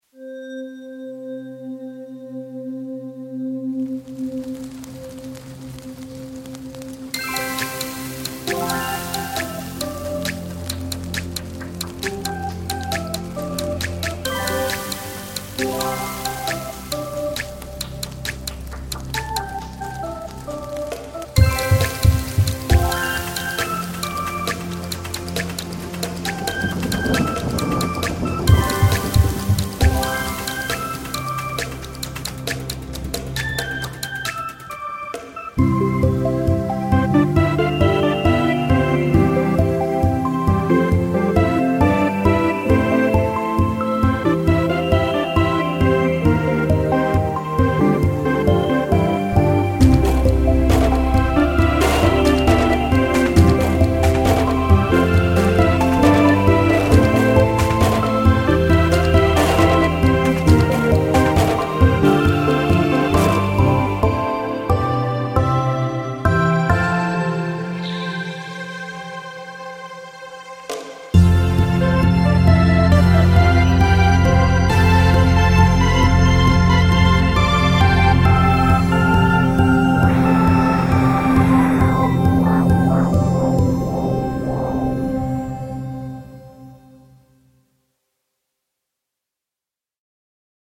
3. 氛围铺底
Rainsong 2 是一款适合任何类型的音乐制作和声音设计的库，它可以让你层叠定制的电子声景，进行基于水的合成，并产生创意的声音风暴。